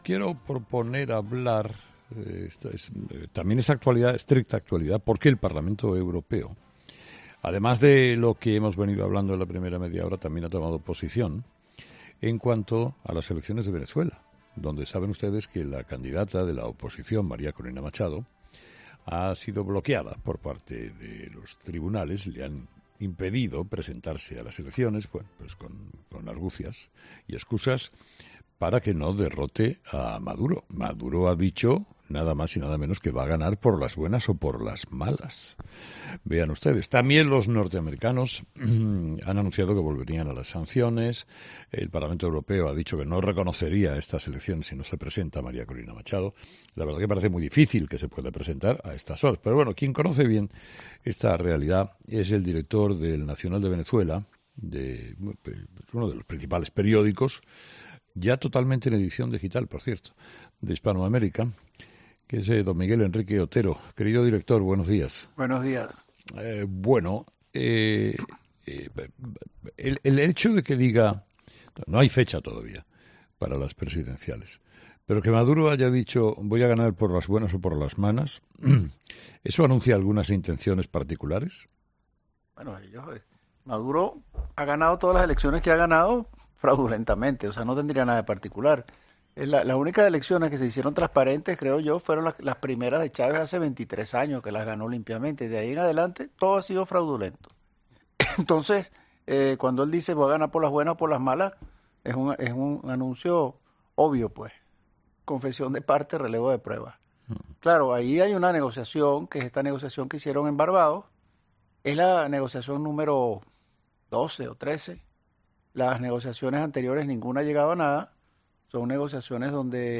Otero es director del diario 'La Nación de Venezuela' y ha asegurado en 'Herrera en COPE' que Maduro "destruyó el 80% del PIB" del país